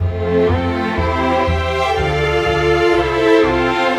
Rock-Pop 11 Strings 01.wav